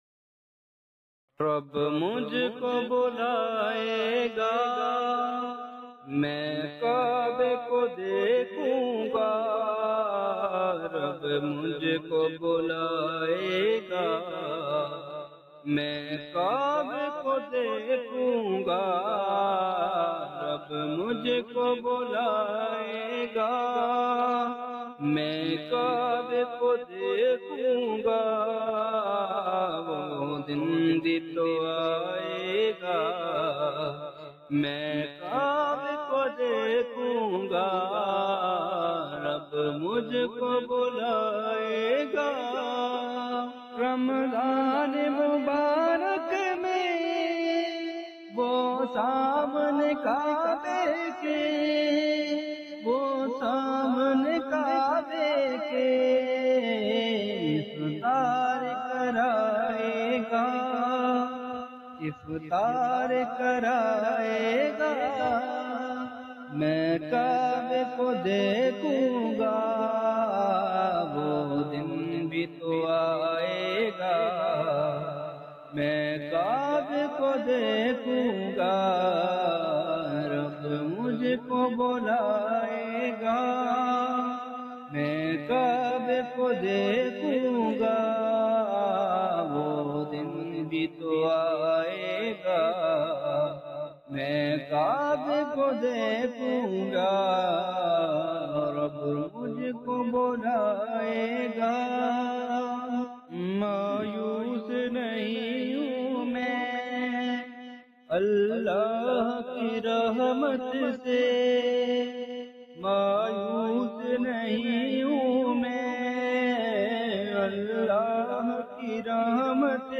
naat